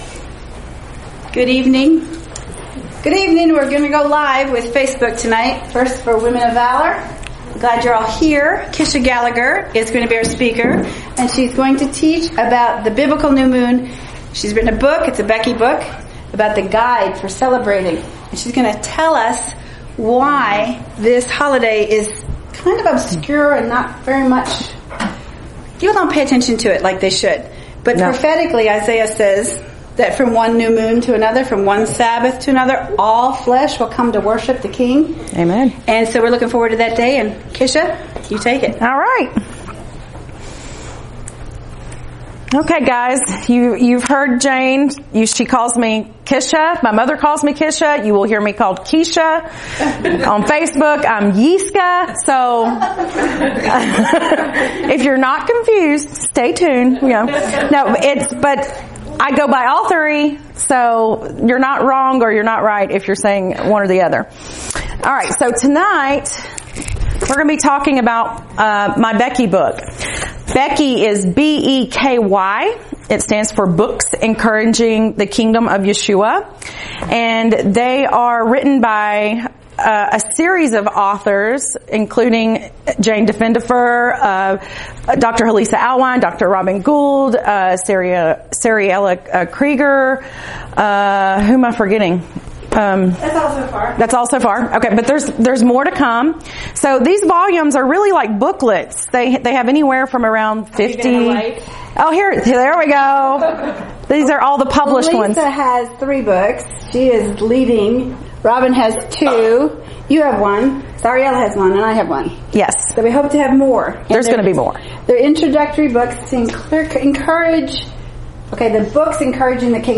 Audio teaching on the new moon and the book